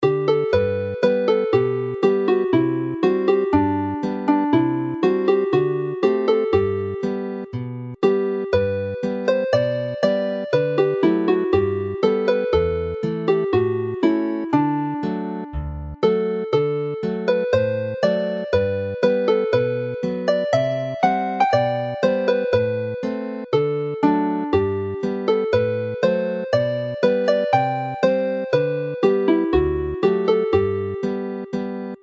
The lyrical Mwynen Merthyr is clearly from the South; Hufen Melyn is a familiar and catchy melody which does not fit into normal dance tune patterns, whilst Gwreiddyn y Pren Ffawydd is a standard 16-bar 2A 2B.